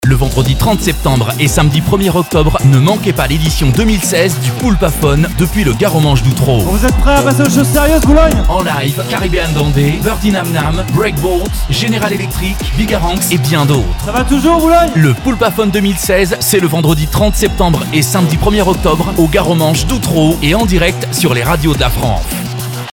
Jingle-promo-POULPA-2016.mp3